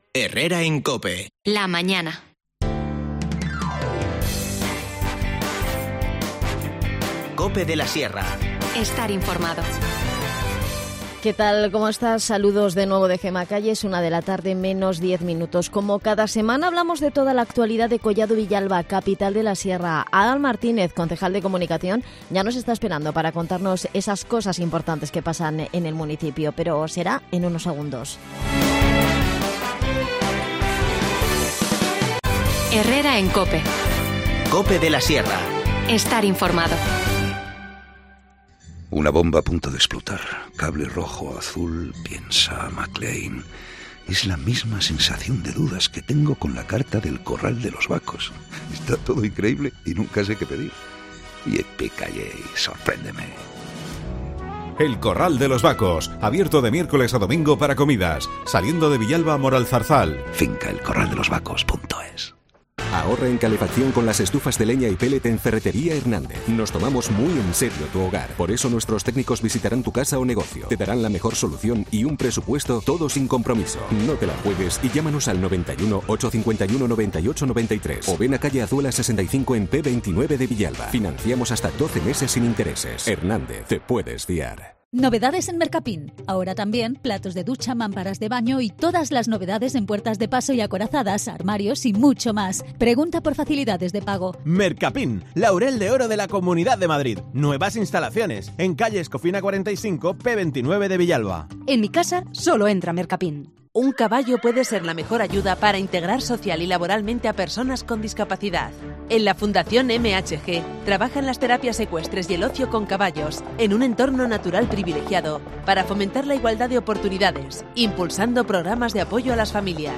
De estas y otras cuestiones hablamos con Adan Martínez, concejal de Comunicación